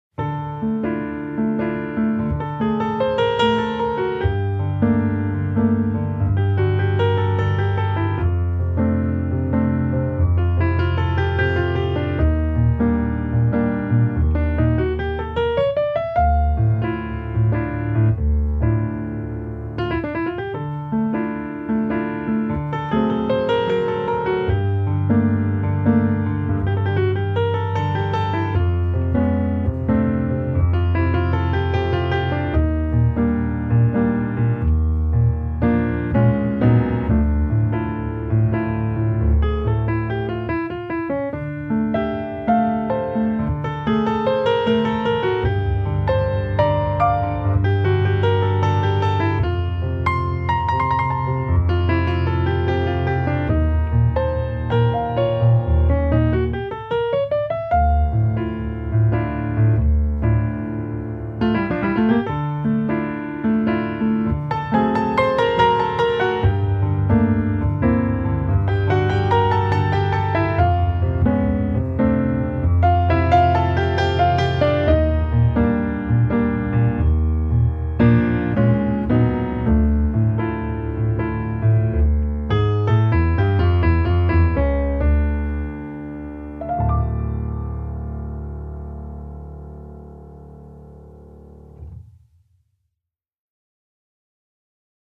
C’est festif mais pas trop.
dans l’auditorium de la Tour des arts des Herbiers